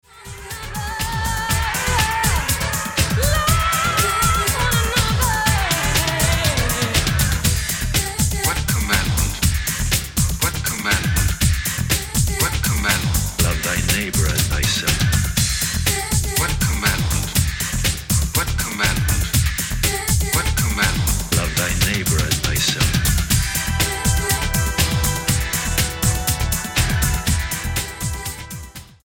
Style: Dance/Electronic